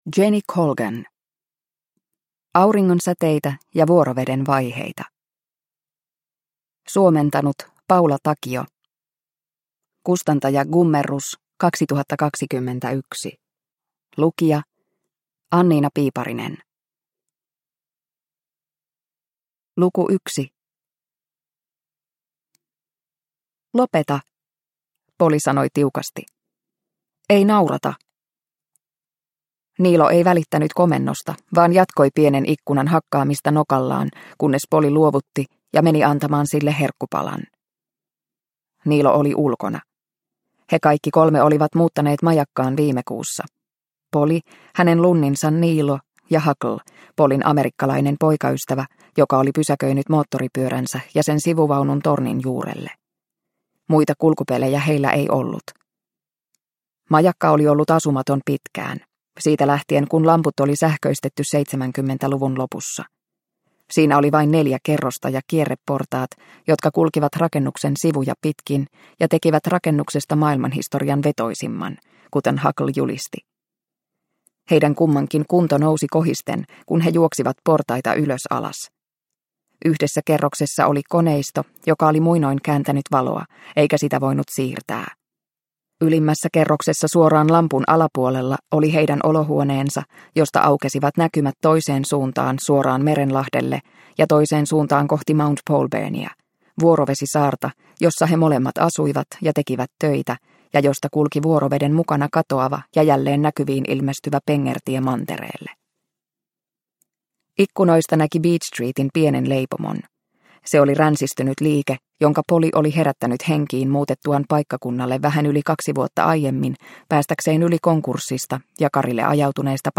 Auringonsäteitä ja vuoroveden vaiheita – Ljudbok – Laddas ner